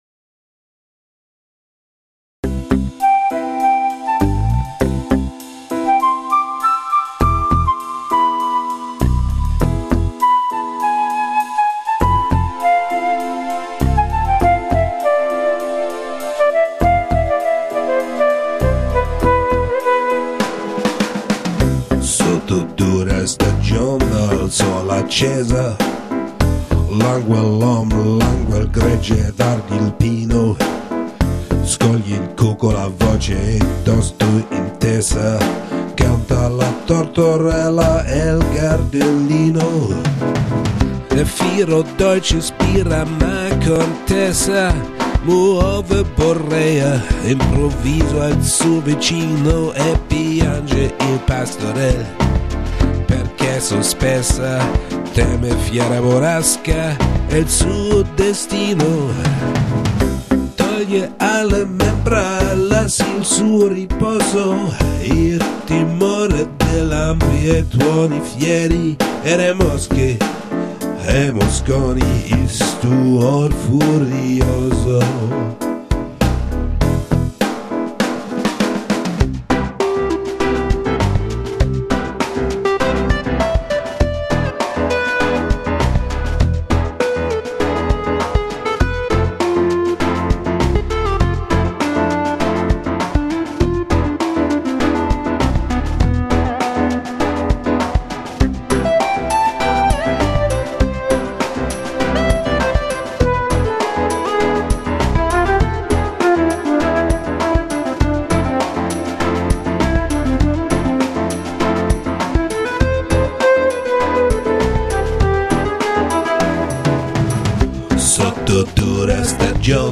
Mein zweites italienisches Gesangsstück